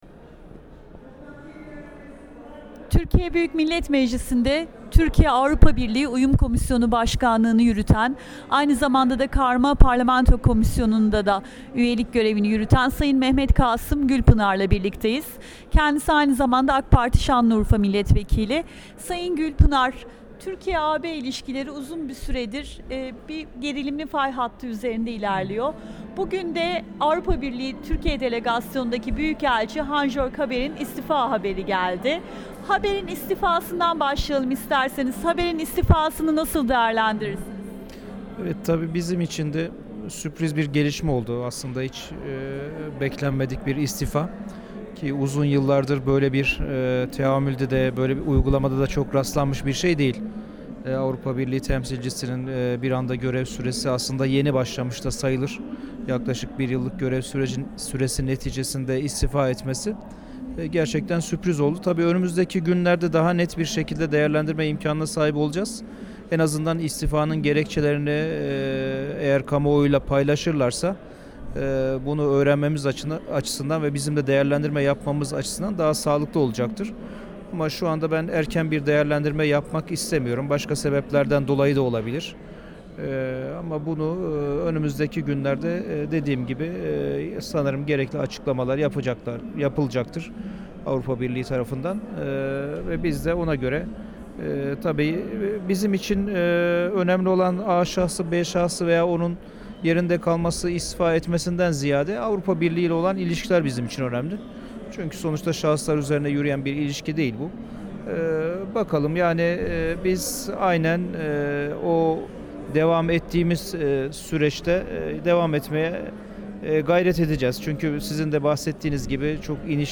Mehmet Kasım Gülpınar'la söyleşi